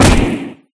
Blow4.ogg